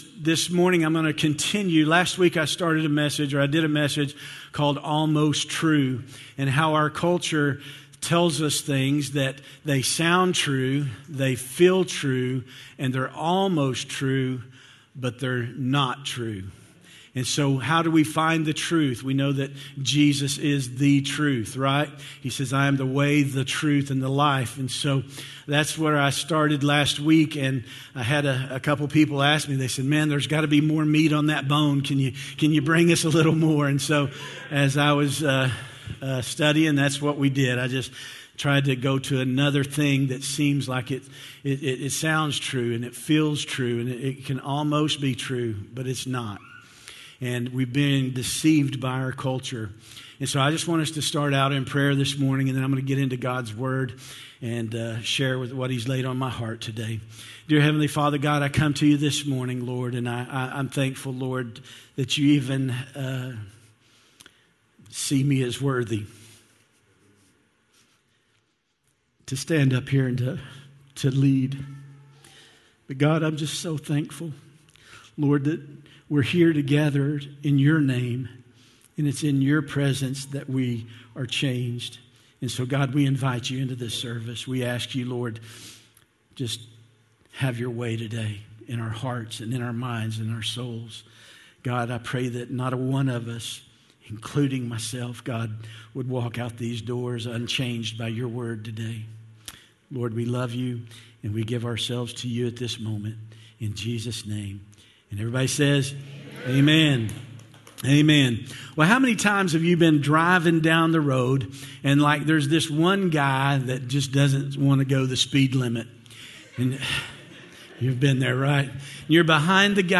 Sermons | Grace Pointe Church